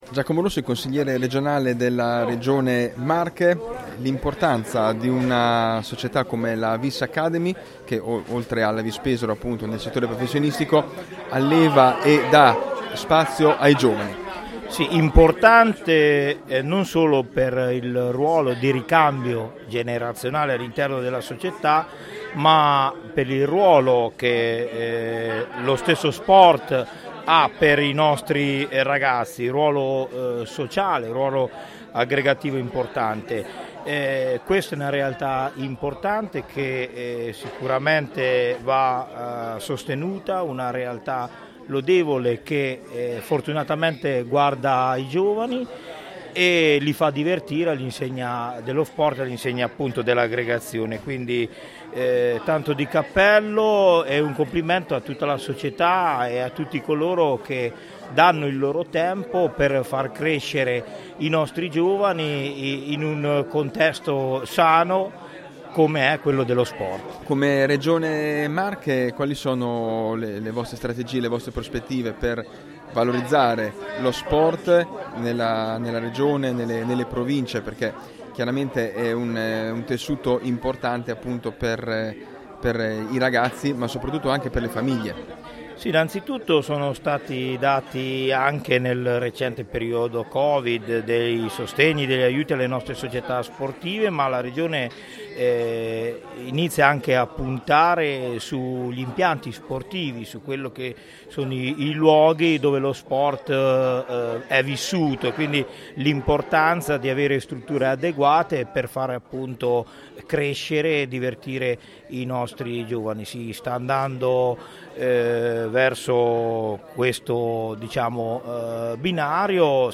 Serata conviviale alla Trattoria Adriatica di Pesaro, dove i vertici della Vis Pesaro Academy hanno riunito lo Staff Tecnico, Autorità e gli Sponsor che sostengono l’attività del settore giovanile Biancorosso. Un lavoro importante a livello educativo e di partecipazione, quello svolto dall’academy poichè con il lavoro di dirigenti e tecnici, si allevano quelli che, si spera, saranno i giocatori del futuro per la prima squadra.